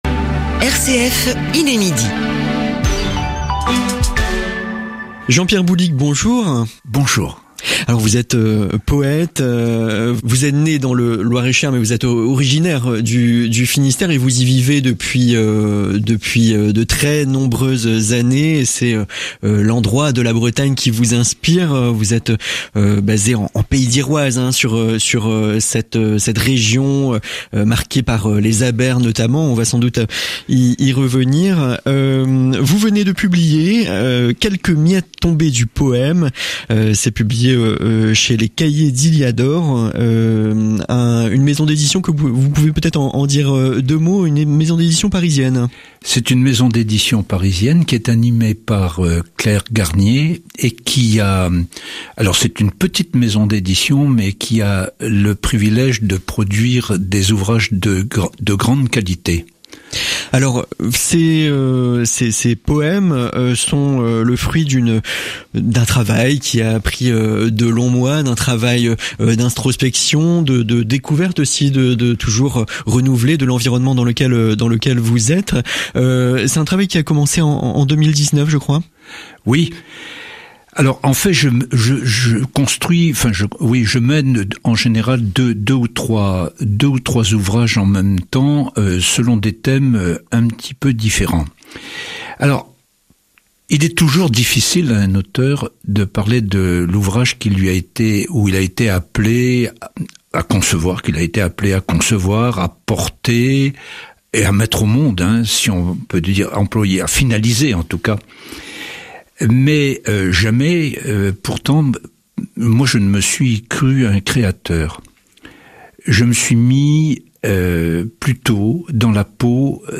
Interview RCF Bretagne